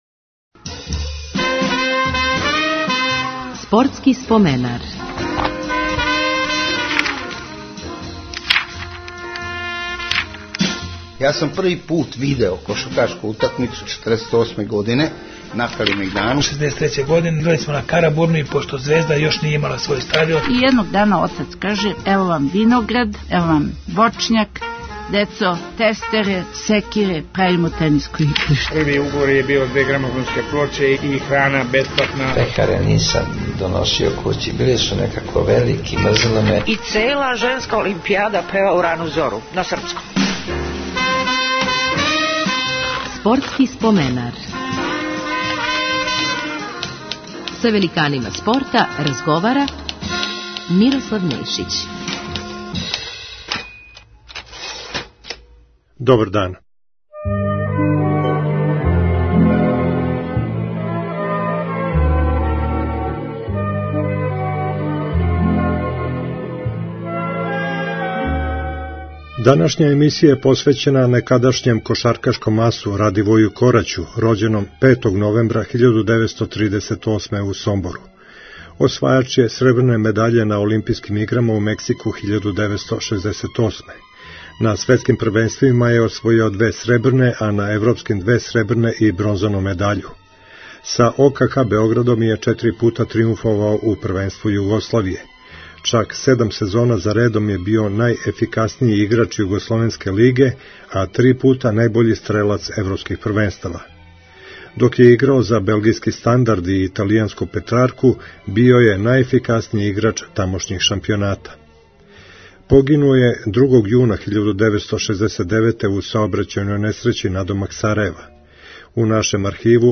У нашем тонском архиву су сачувана само два-три минута записа Кораћевог гласа, па су у емисији искоришћена сећања саиграча, тренера и поштовалаца великог аса - гостију Спортског споменара.